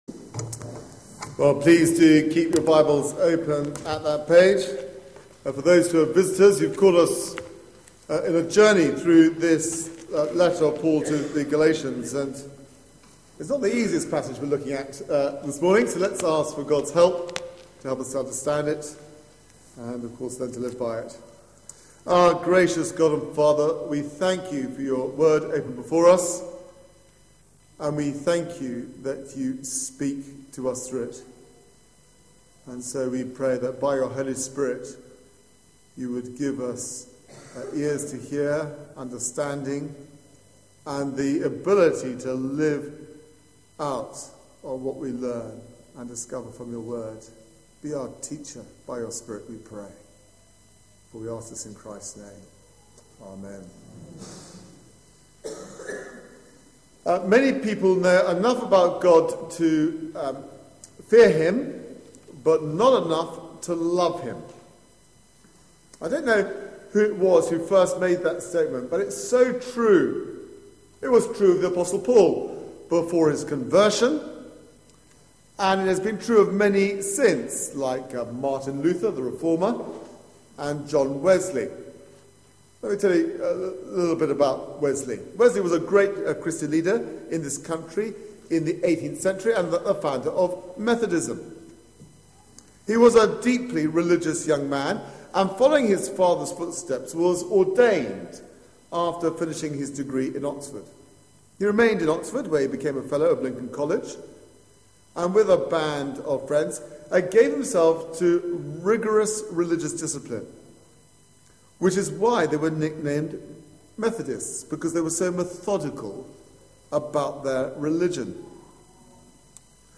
Media for 9:15am Service on Sun 24th Feb 2013 09:15 Speaker
Sermon